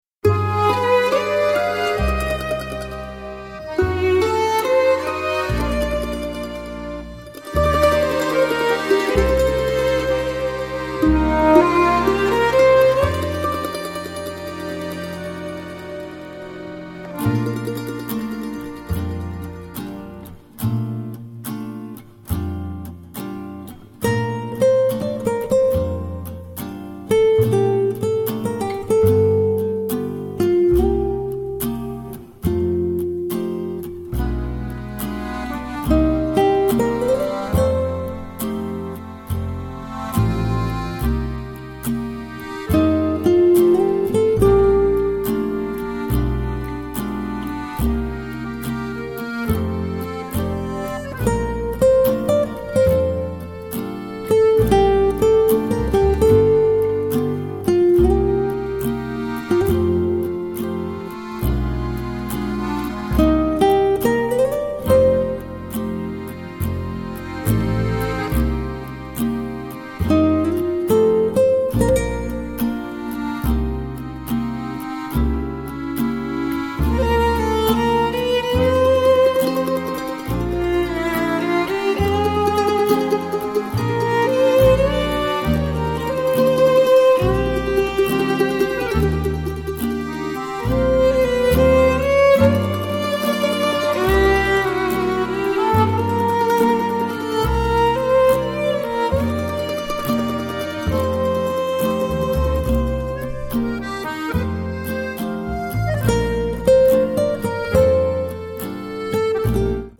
★ 曼陀鈴與手風琴的合奏盡顯義大利音樂的地方民俗特色。
★ 聆聽時彷彿置身在水都威尼斯，悠閒的氛圍無價！
★ 音色的彈性 Q 感，以及合奏時自然的細節不容錯過！
曼陀鈴與手風琴的合奏，輕輕幾聲就能將這種搖曳的風情呈現出來，同時又能顯出一種獨特的「淡淡的哀傷」。